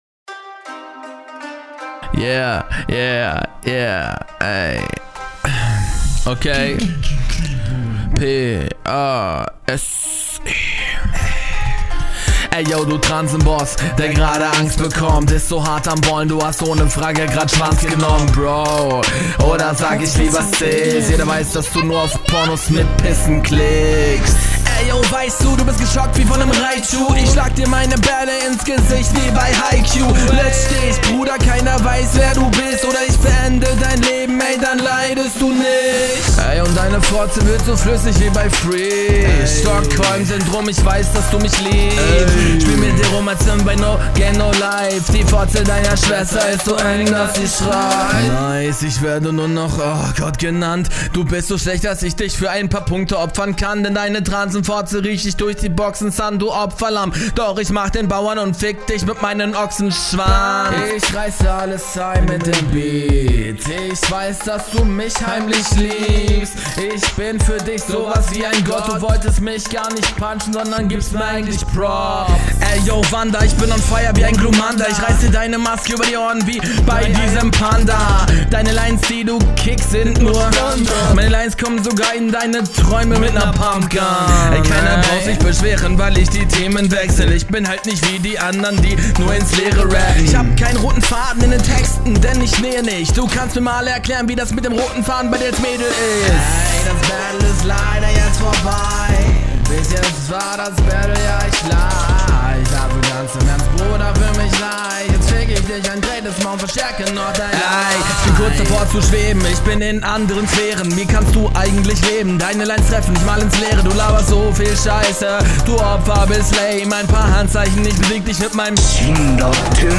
Hook ist mega cool.
schon wieder über 3 min :( Ansonsten ganz cool Punches warn dabei,flow passt Stimmeisatz und …